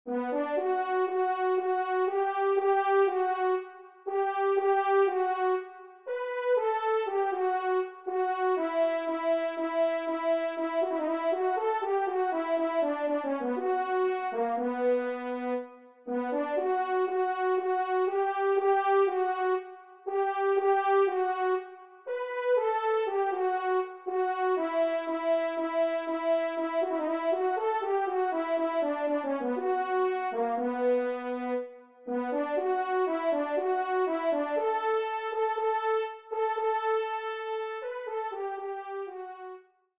ENSEMBLE  (solo)